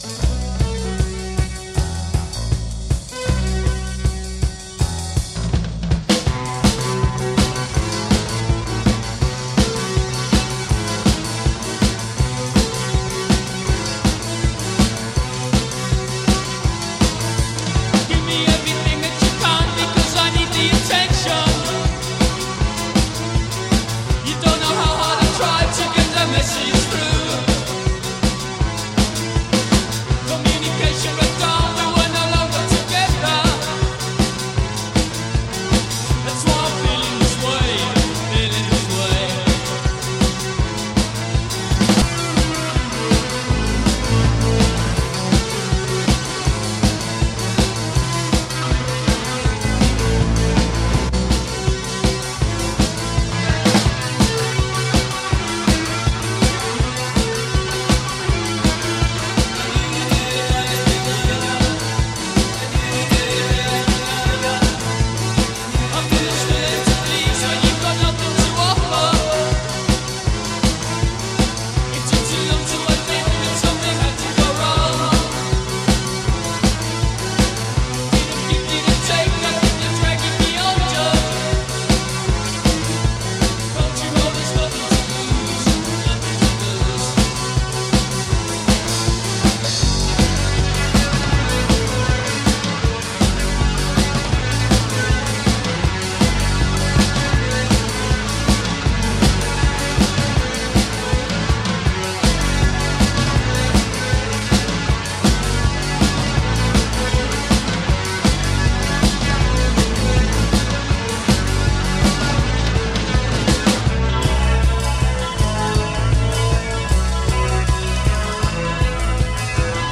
Most probably from the early 80s.